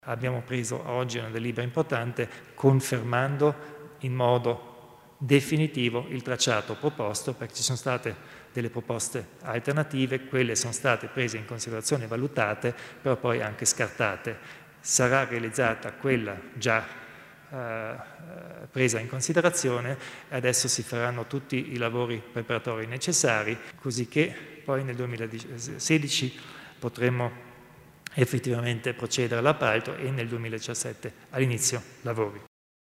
Il Presidente Kompatscher spiega il futuro della circonvallazione di Merano